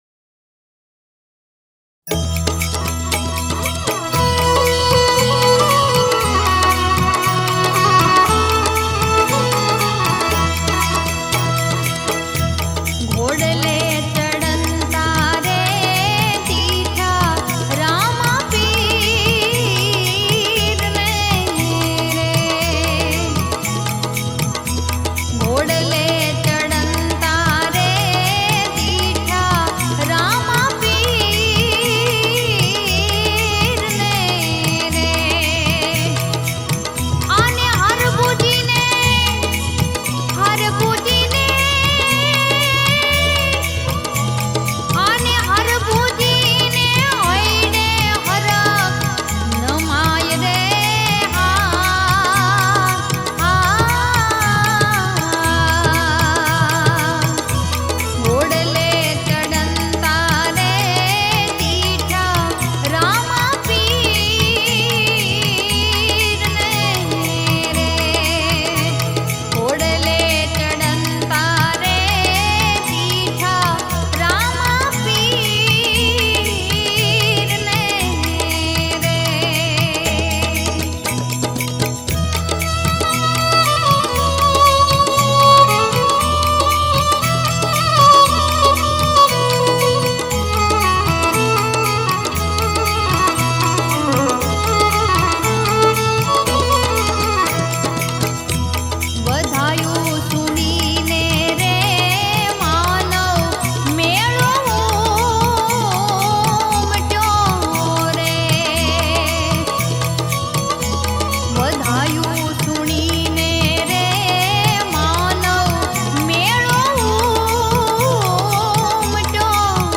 Gujarati Bhajan
Ramdevji Bhajan